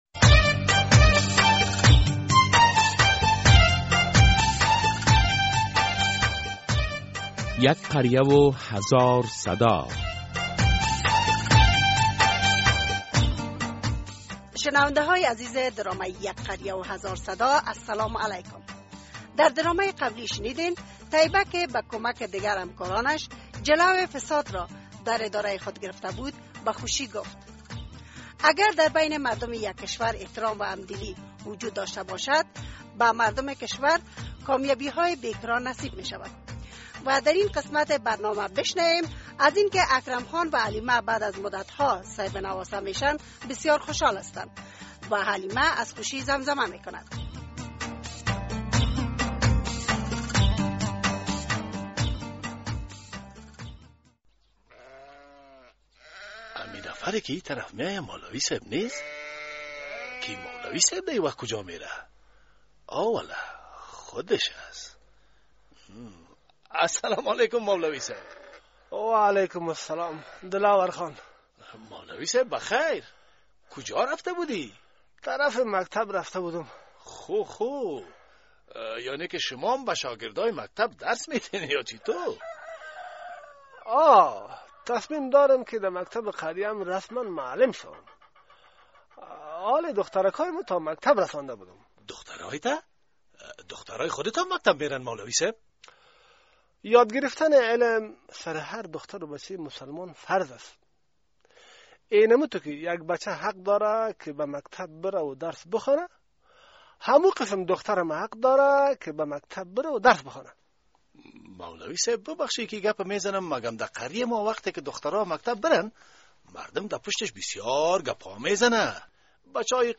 درامه یک قریه هزار صدا قسمت ۲۱۷